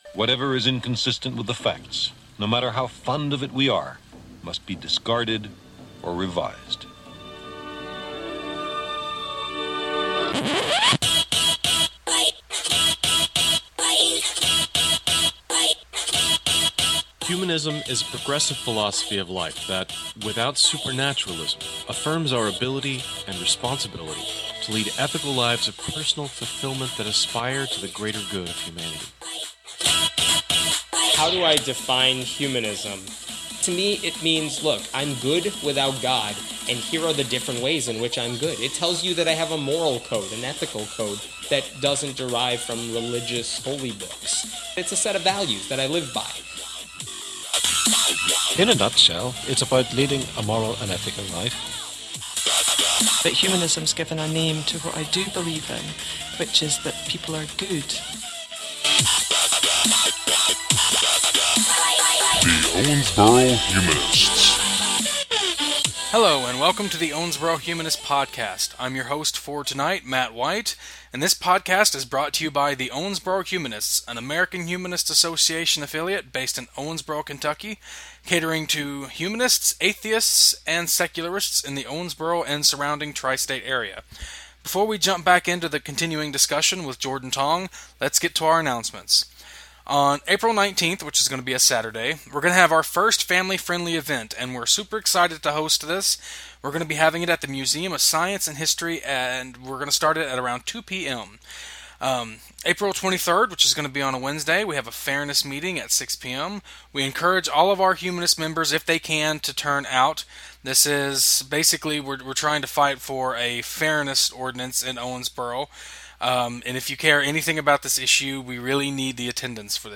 Debate Part 2 – A Christian and an Athiest Discuss Faith, Free Will, and the Bible